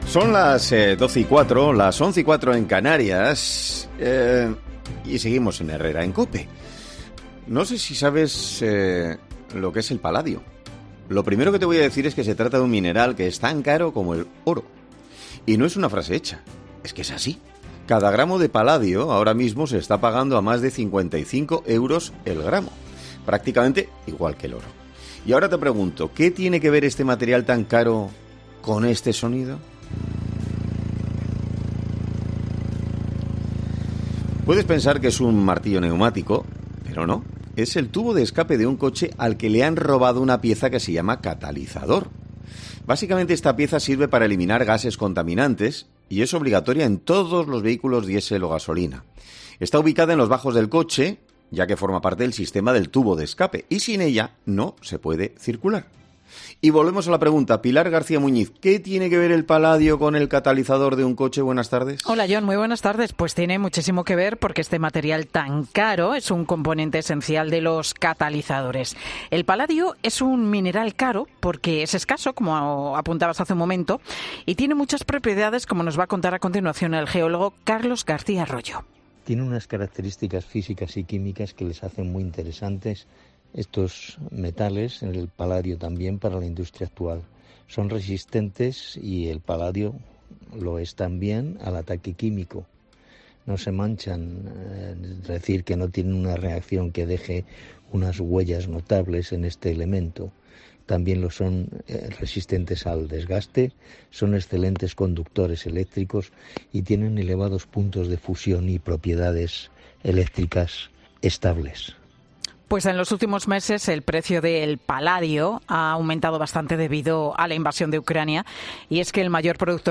En 'Herrera en COPE' hablamos con un afectado que nos explica lo que le ocurrió y analizamos cómo las bandas se dedican a robar estas piezas que...